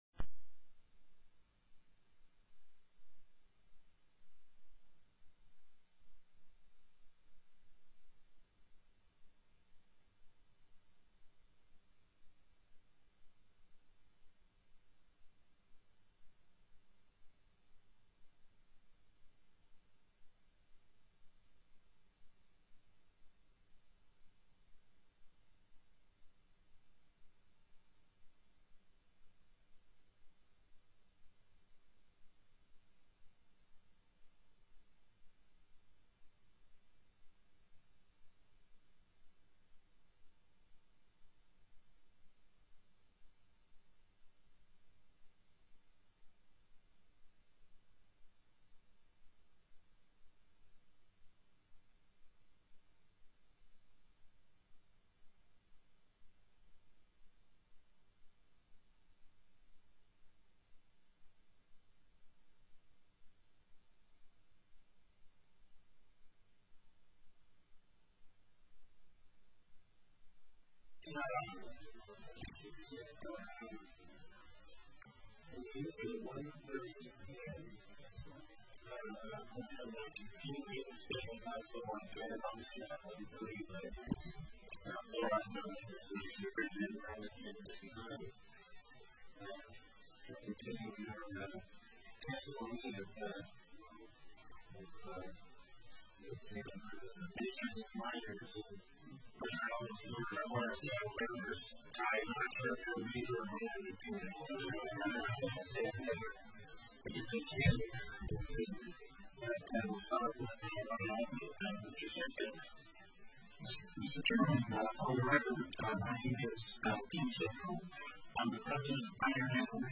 03/23/2011 01:30 PM House FINANCE
TELECONFERENCED
Industry Testimony